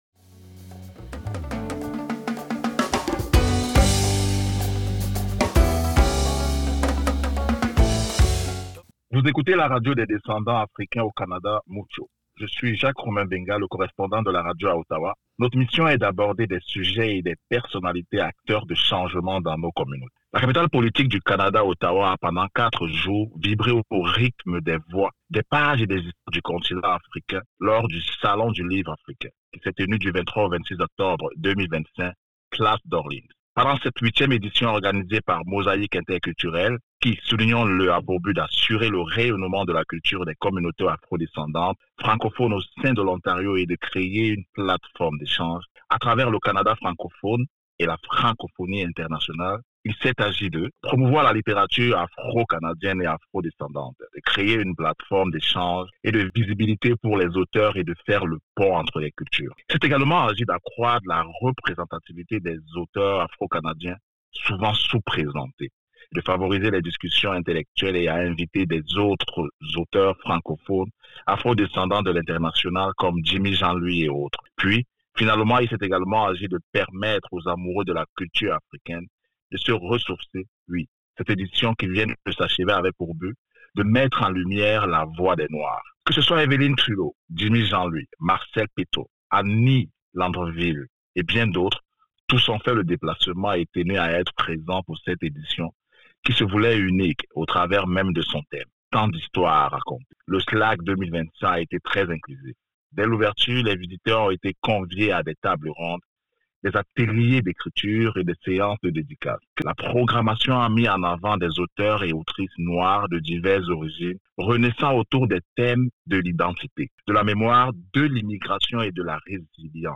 Jimmy Jean Louis au sallon du livre Ottawa SLAC 2025